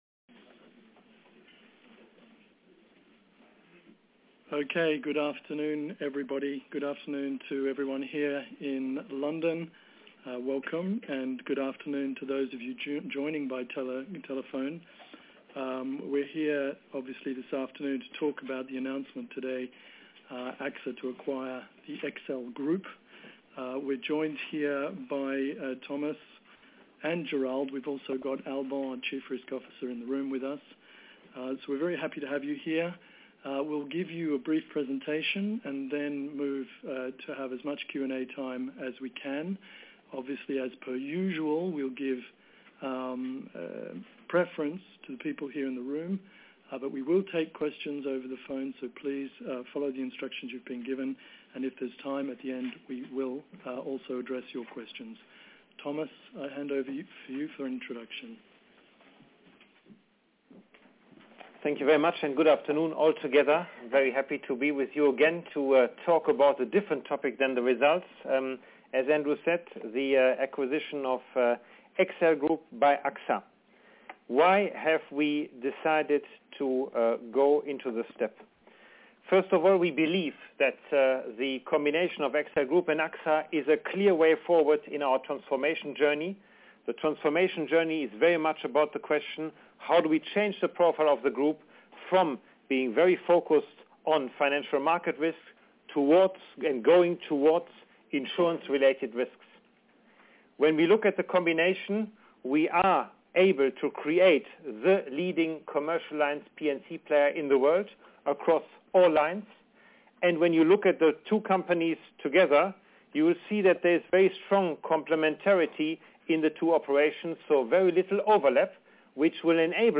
Media Conference